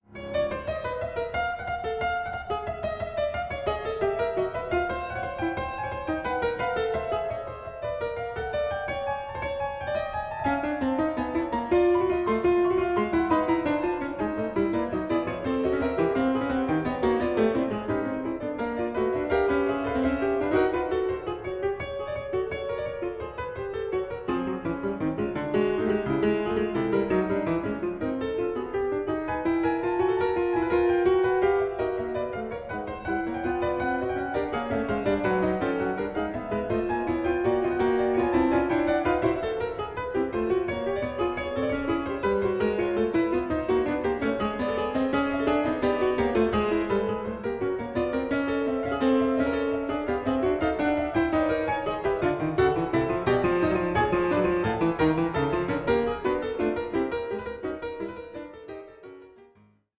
in D-Flat Major: Prelude 2:54